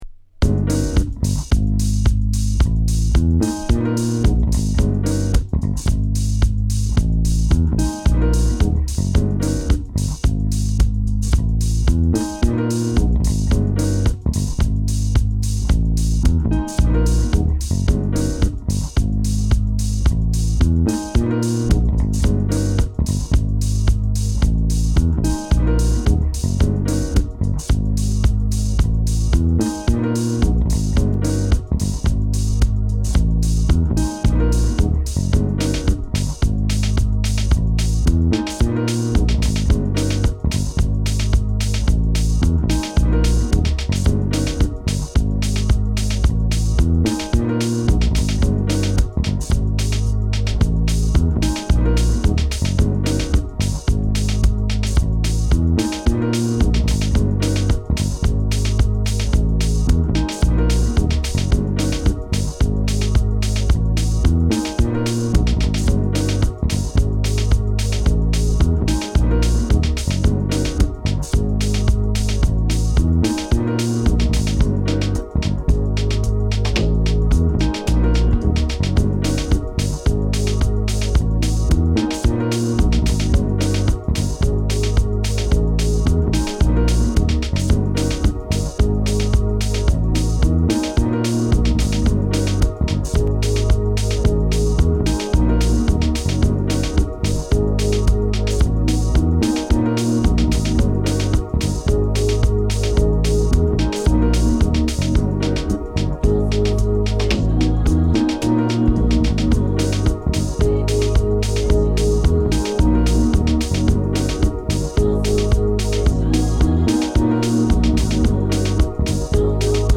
Deep House
Electronic